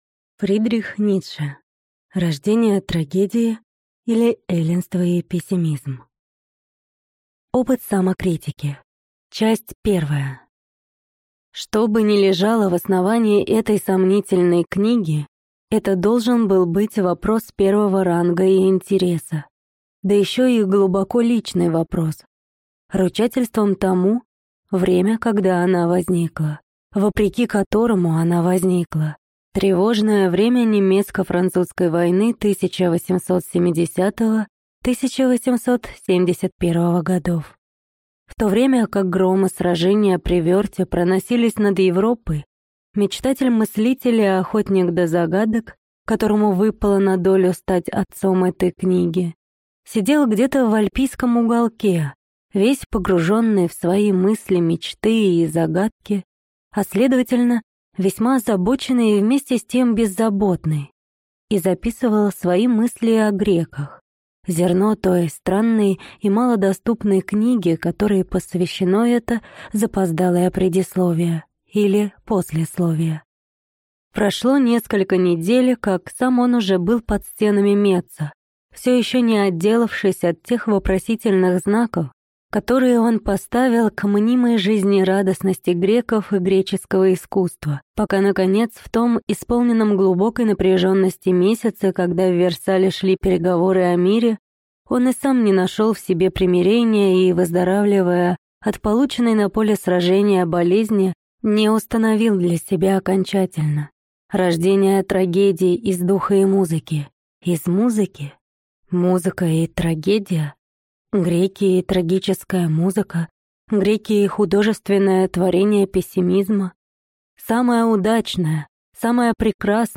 Аудиокнига Рождение трагедии, или Эллинство и пессимизм | Библиотека аудиокниг